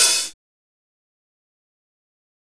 OpenHH Groovin 1.wav